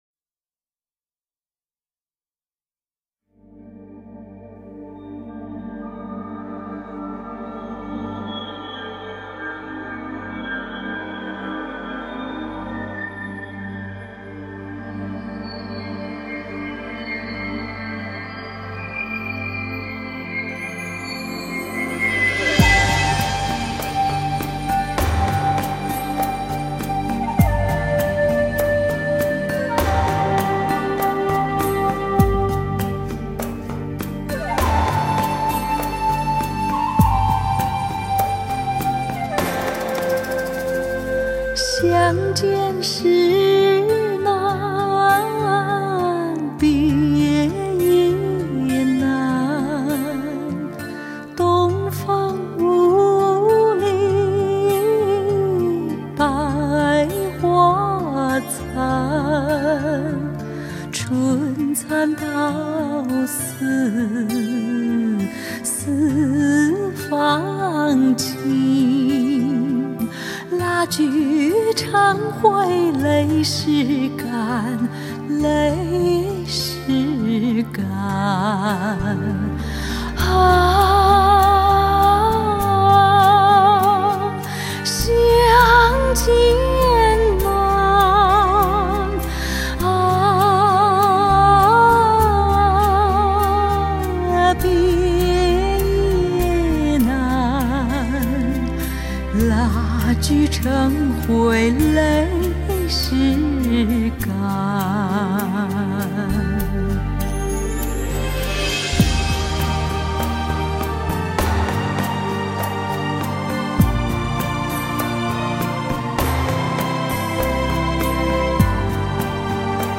辑完全是一张性感的.柔软的.流畅的.煸情的沙发流行乐,每个音符都清